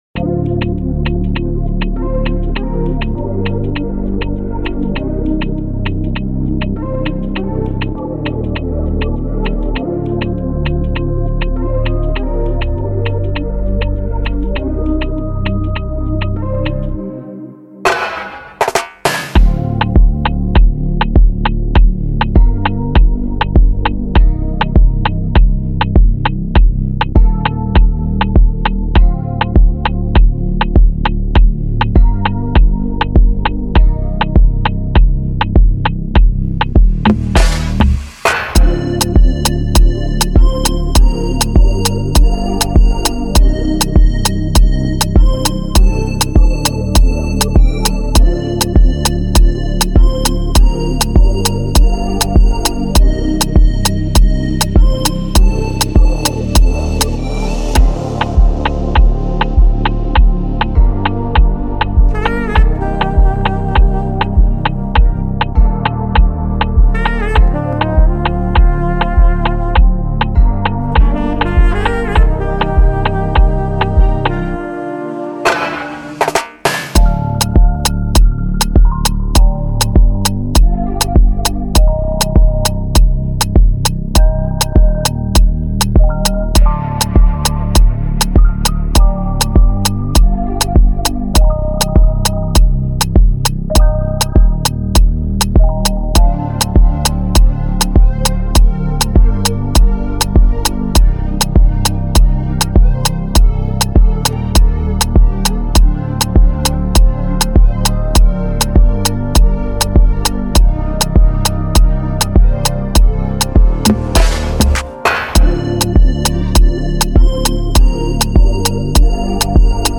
2023 in Dancehall/Afrobeats Instrumentals